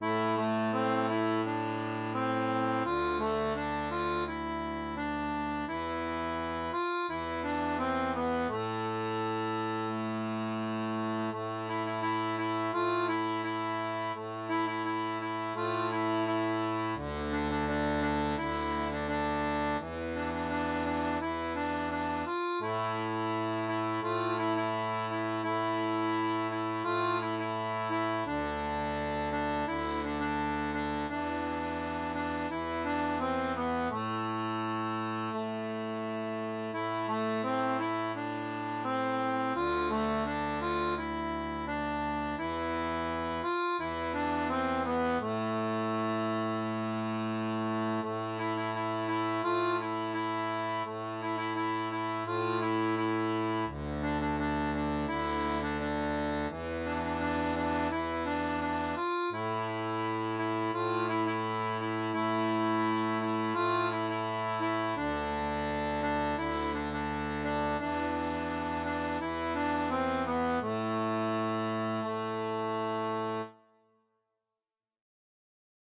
Version simplifiée (idéale pour débuter)
Pop-Rock